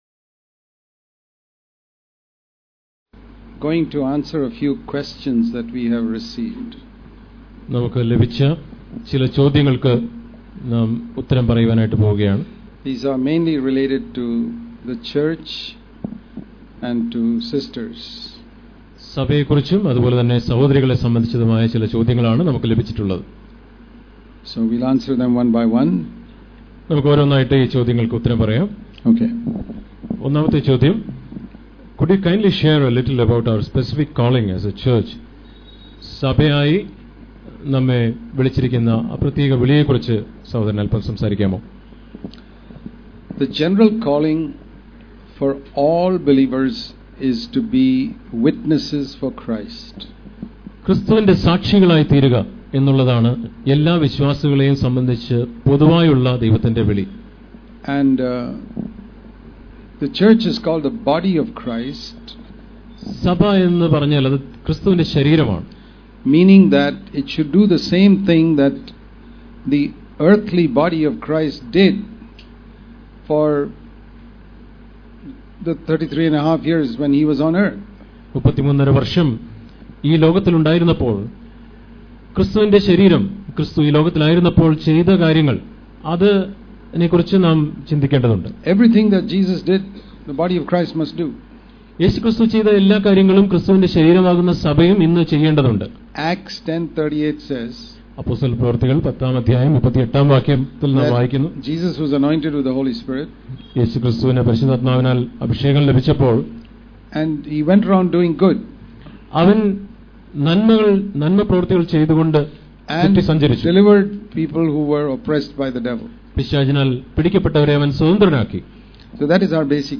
സഭ, സഹോദരിമാർ - ചോദ്യോത്തരം
Question & Answer On The Church And On Women Living The Christ-Life In His Church
question-and-answer-on-the-church-and-on-women.mp3